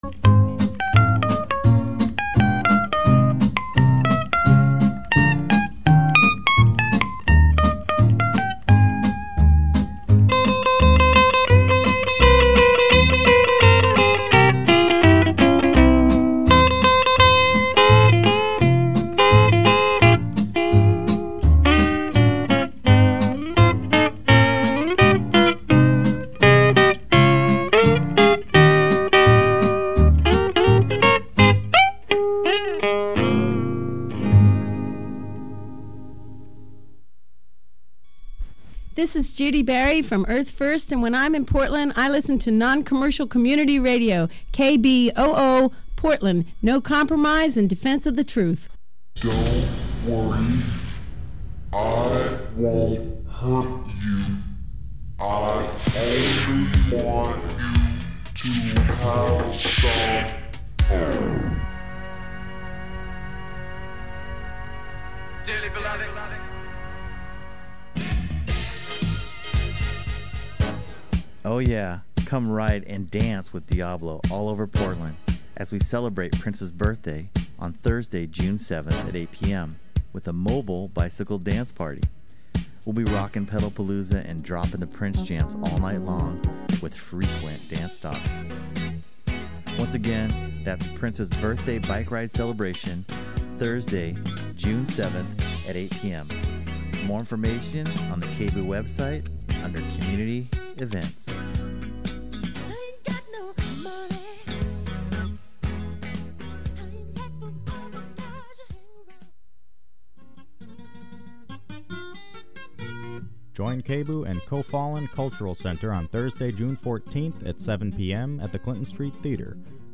Download audio file The Final Ubu Hour was two months ago, this is a rebroadcast of Alfred Jarry's Ubu Enchained, as done by the Ubu Hour people. It originally ran during the 101 Hour Dada fest in 2008, but here's a chance to hear it again..